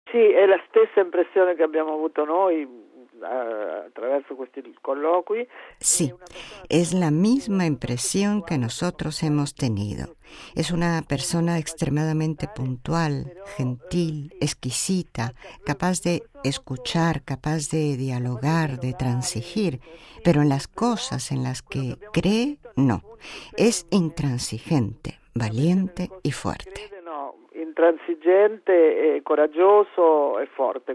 El volumen - en forma de entrevista- es del año 2010 y se traducirá en diversos idiomas.